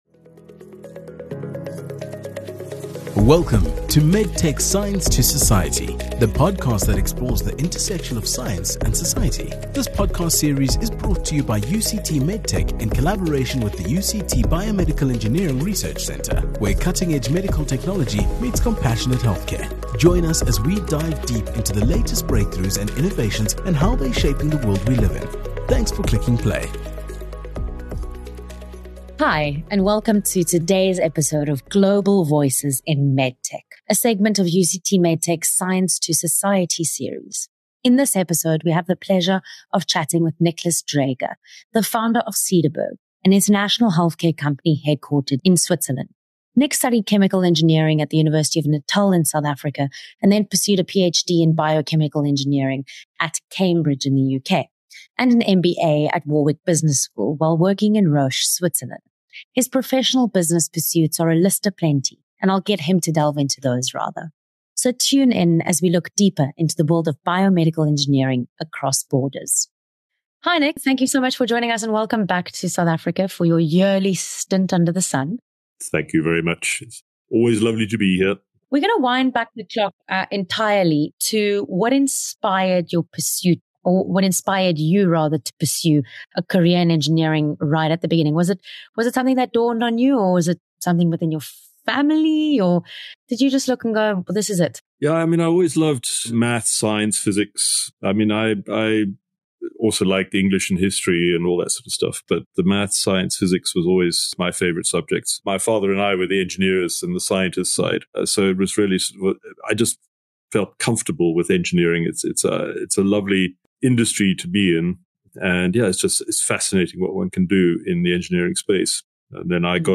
21 Feb MedTech Science to Society E7: A Conversation